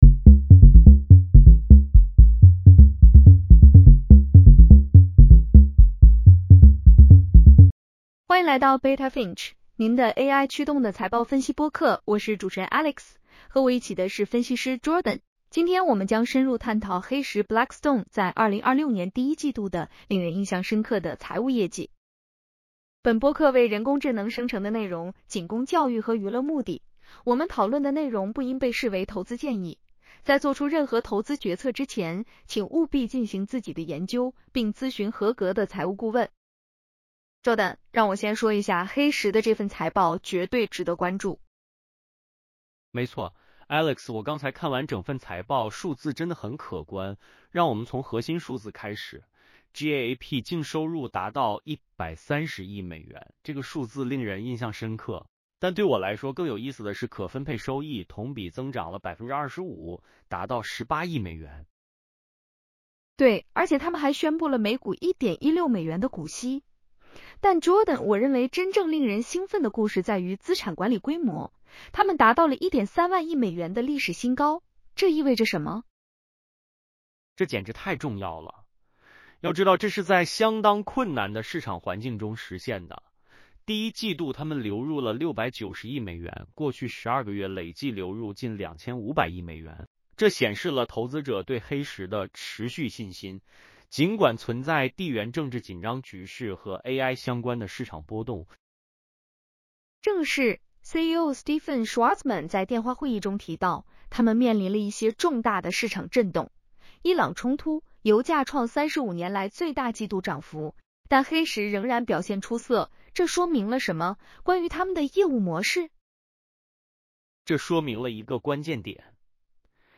本播客为人工智能生成的内容,仅供教育和娱乐目的。